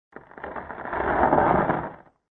Descarga de Sonidos mp3 Gratis: grieta 1.